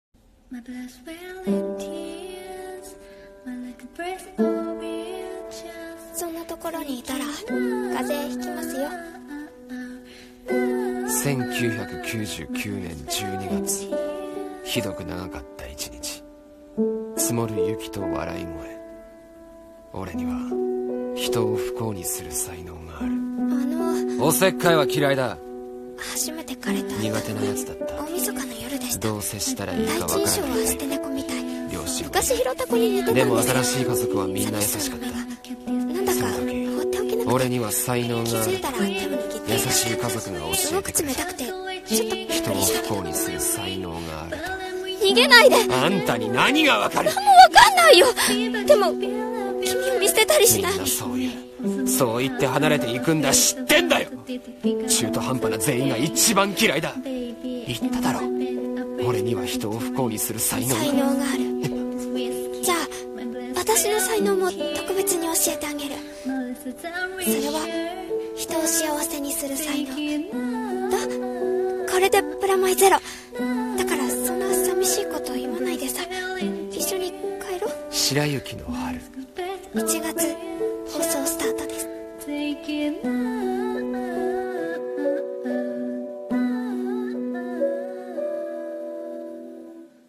【予告風台本】白雪の春。 / 声劇台本